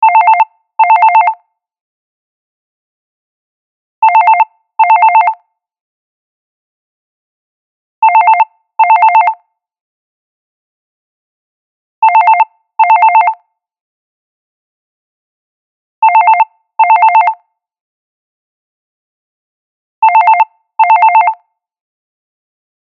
phone-ringtone-telephone-324474.mp3